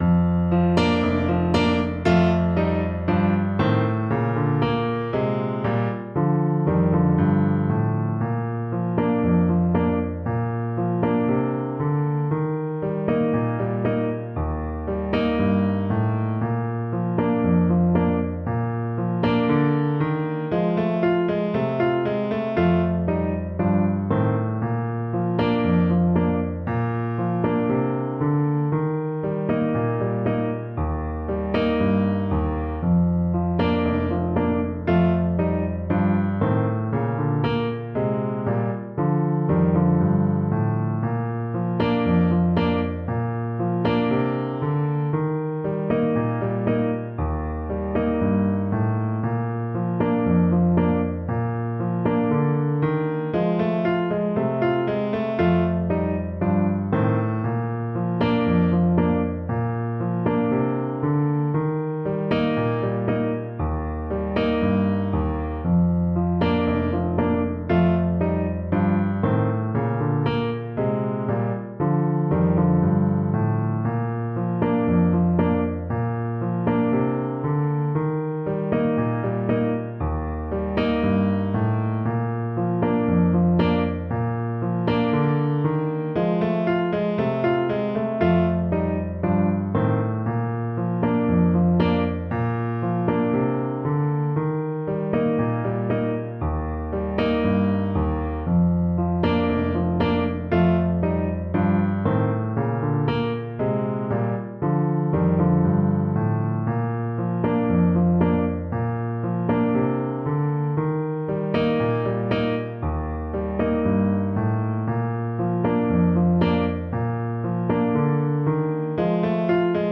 Play (or use space bar on your keyboard) Pause Music Playalong - Piano Accompaniment Playalong Band Accompaniment not yet available transpose reset tempo print settings full screen
Clarinet
4/4 (View more 4/4 Music)
Bb major (Sounding Pitch) C major (Clarinet in Bb) (View more Bb major Music for Clarinet )
Moderato =c.90
Traditional (View more Traditional Clarinet Music)